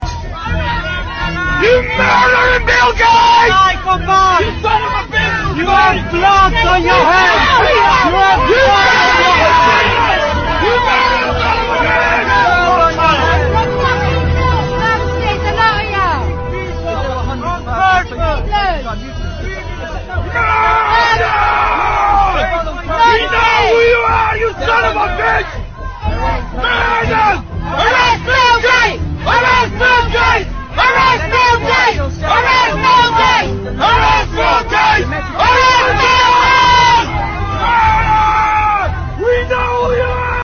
Hier wird das Fahrzeug von Bill Gates von wütenden Menschen umzingelt, welche ihn begeistert empfangen....